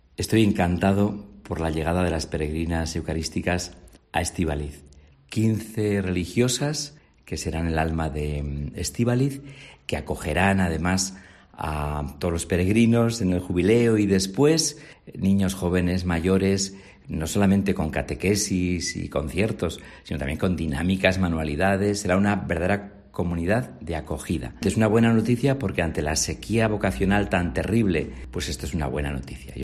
Juan Carlos Elizalde, obispo de Vitoria